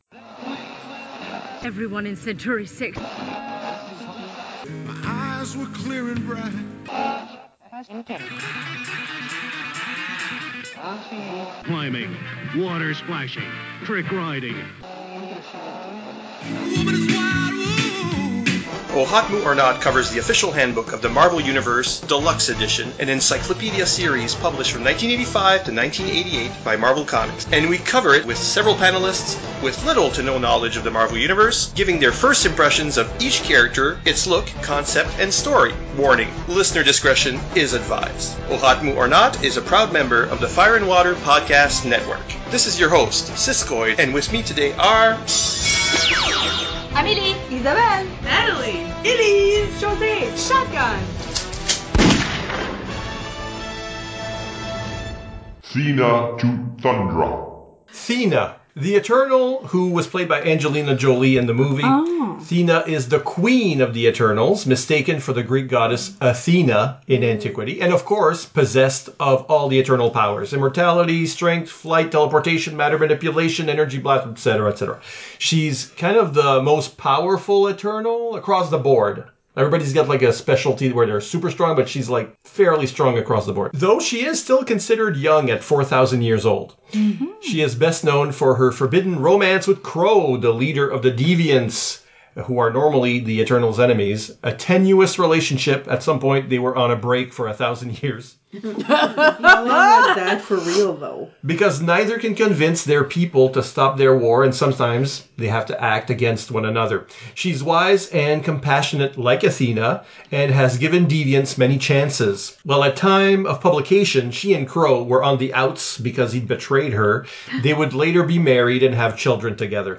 Listen to Episode 111 below (the usual mature language warnings apply), or subscribe to oHOTmu OR NOT? on Apple Podcasts or Spotify!